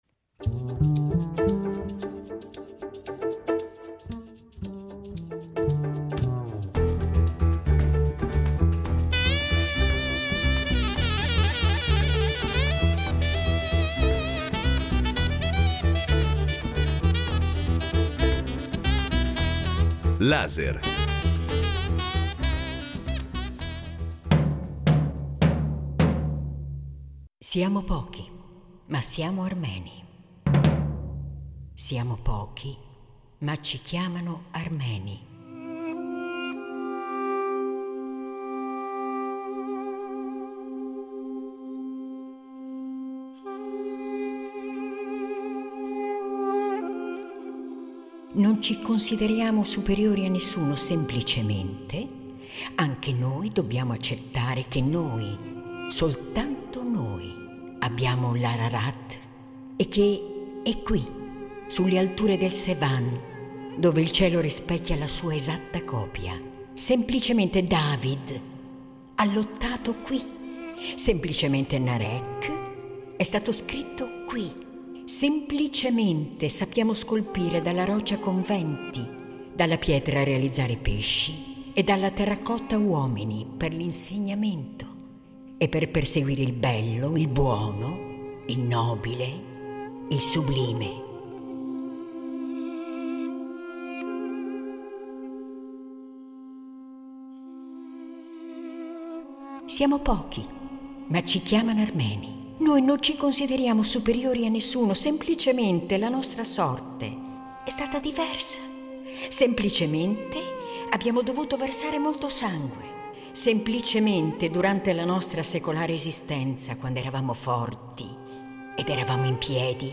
con la voce dell’attrice